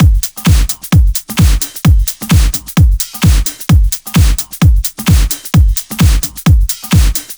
VFH2 130BPM Comboocha Kit 1.wav